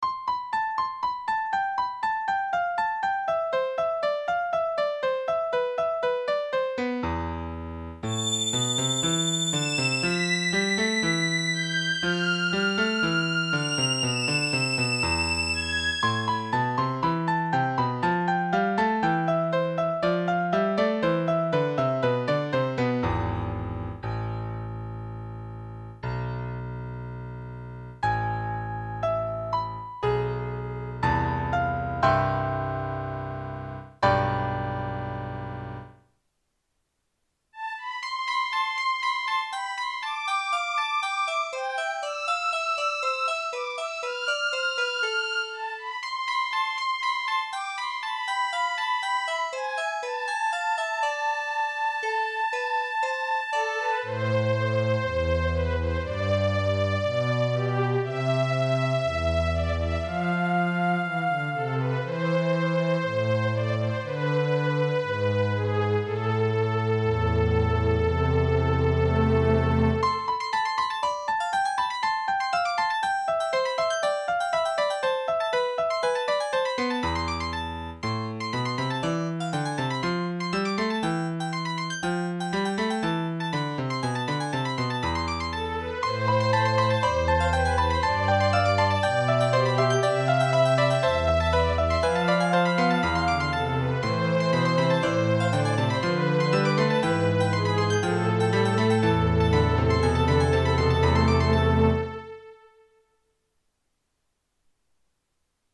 MIDとGuitarの曲